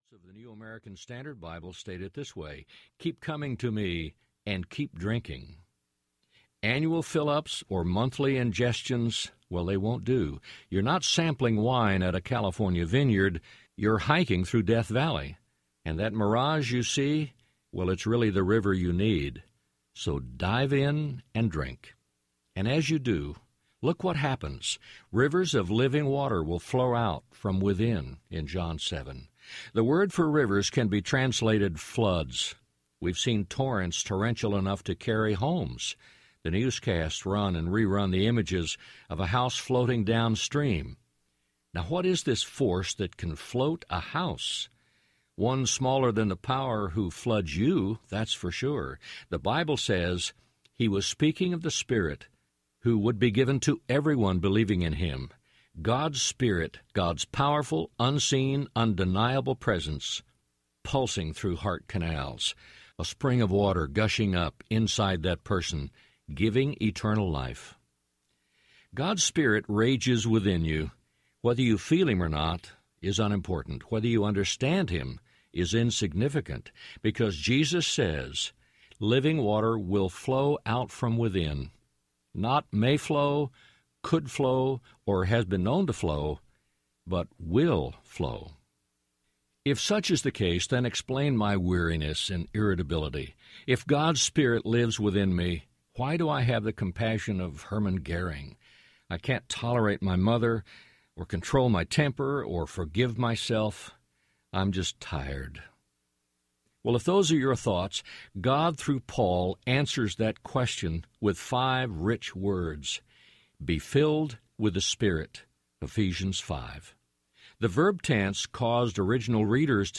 Come Thirsty Audiobook
Narrator
3.5 Hrs. – Unabridged